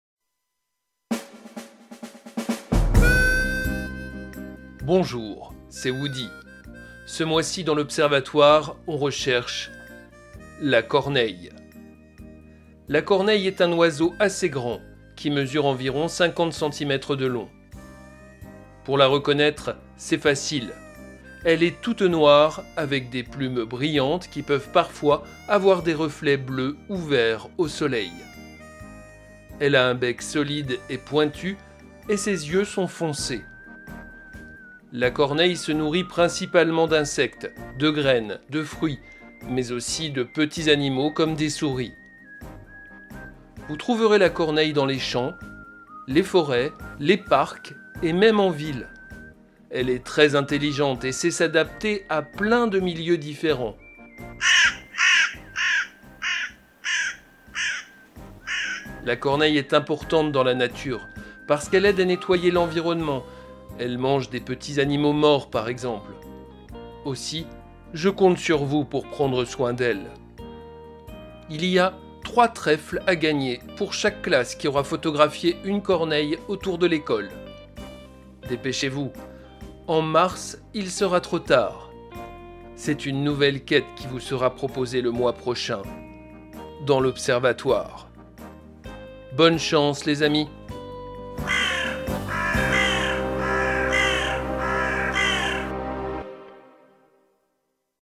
Sons_woody_corneille.mp3